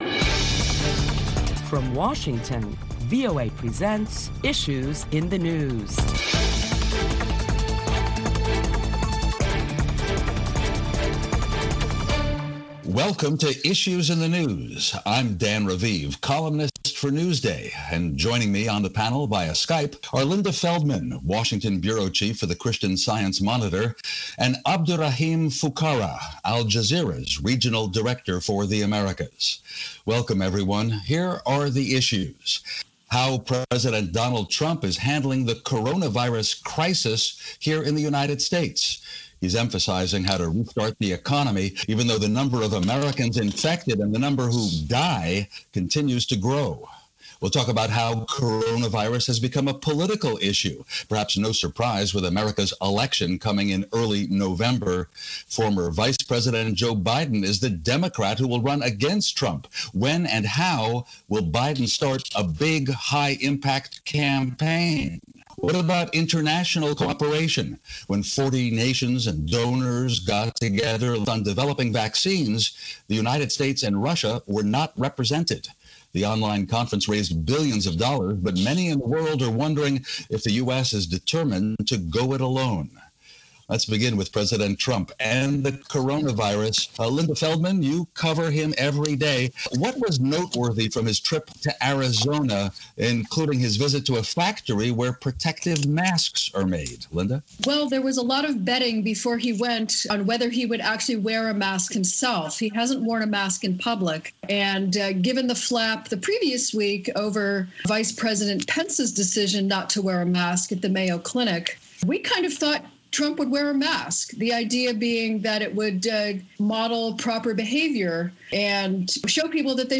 Listen to Issues in the News where you will hear a panel of prominent Washington journalists deliberate the latest top stories of the week.